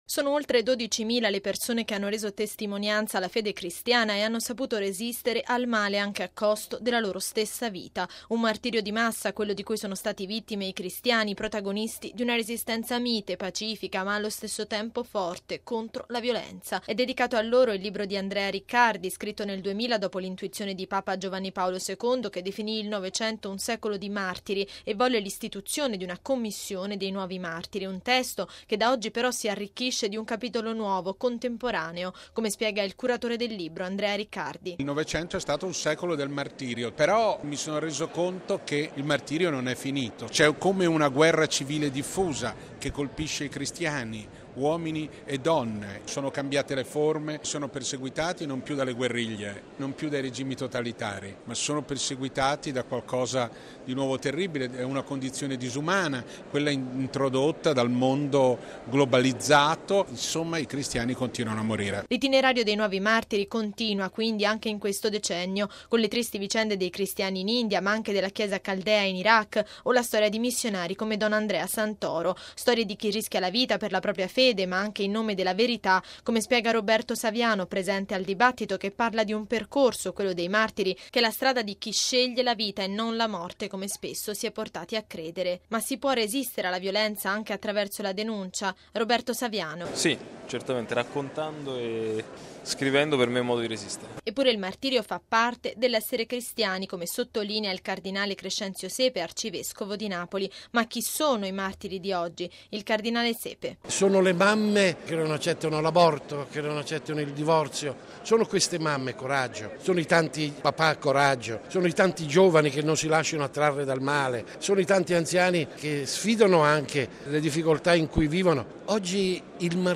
A Roma un incontro sulla testimonianza dei martiri cristiani nel Novecento. Con noi, il cardinale Sepe, il prof. Riccardi e il giornalista Saviano
Il testo, presentato ieri a Roma nella Basilica di San Bartolomeo all’isola, ha quindi offerto un’occasione di dibattito sul tema del male e della violenza.